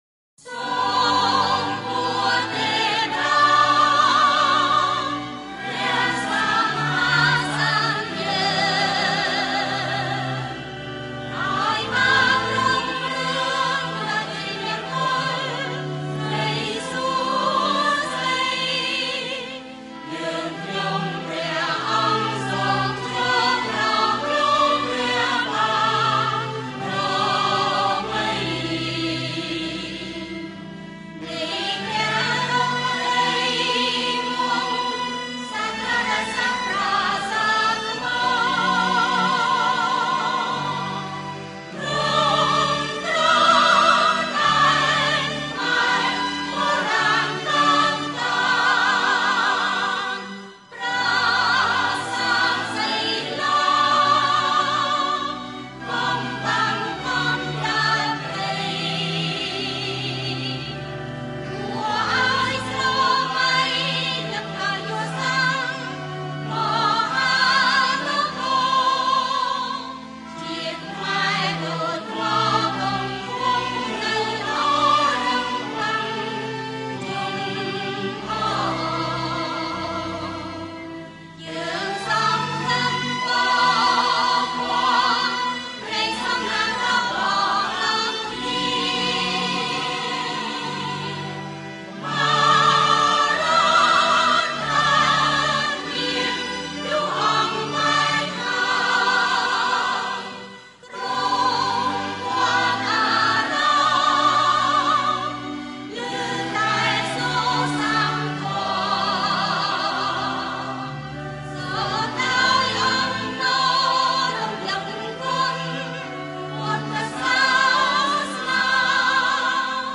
хорошее качество